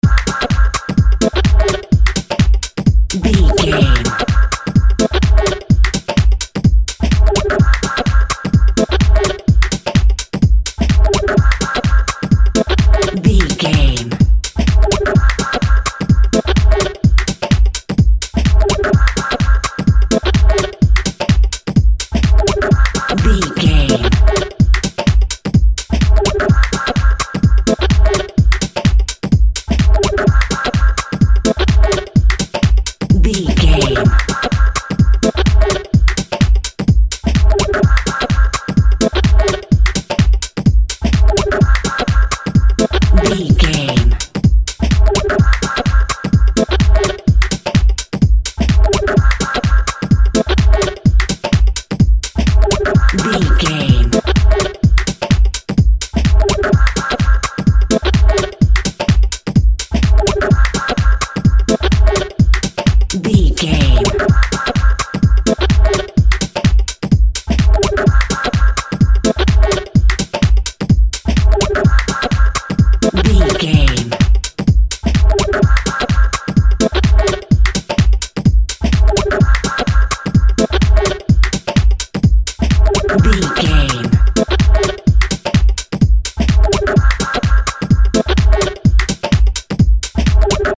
Epic / Action
Fast paced
Aeolian/Minor
intense
futuristic
energetic
driving
repetitive
dark
synthesiser
drum machine
progressive house
club music
synth bass